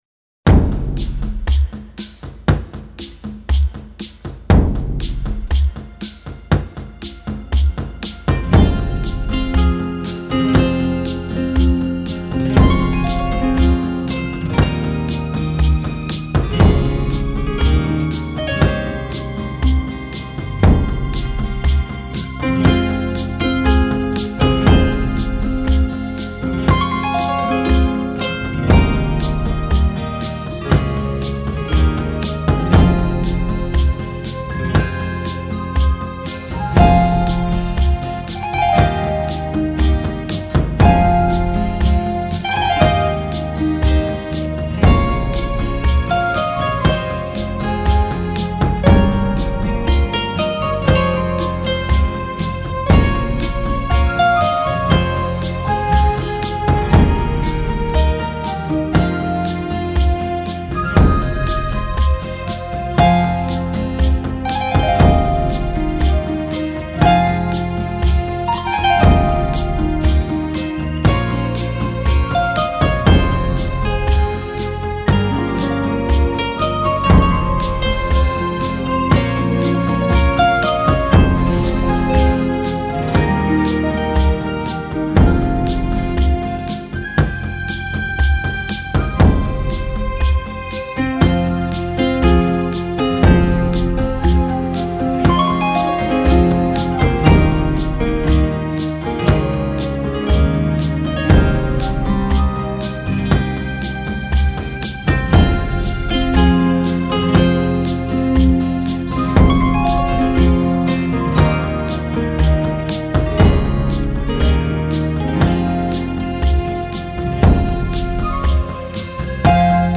以打击乐器及鼓融合非洲原住民祭祀歌曲，